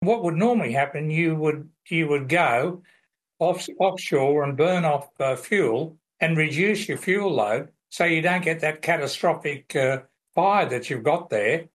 Aviation expert